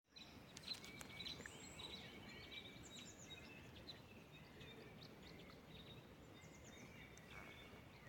Krustknābis Loxia sp., Loxia sp.
Skaits13